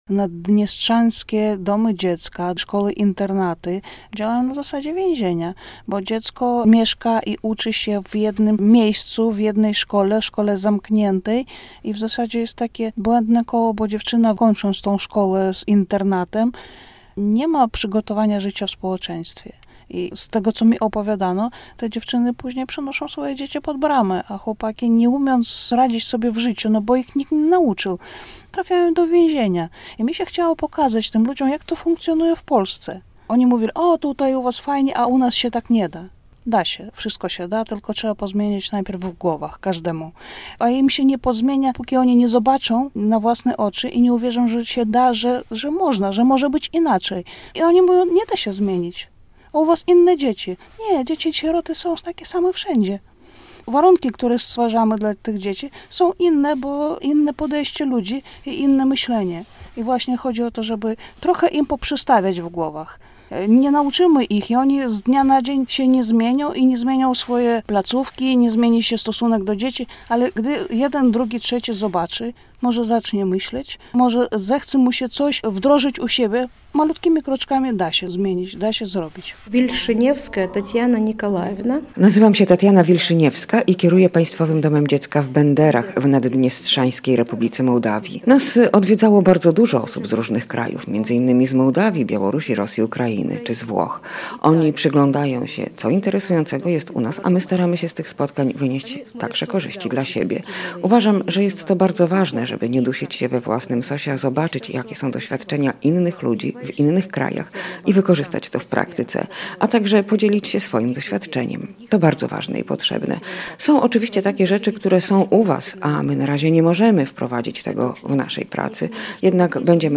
Hajnowska Fundacja „Oni To My” odwiedza ośrodki wychowawczo-opiekuńcze w Naddniestrzu - relacja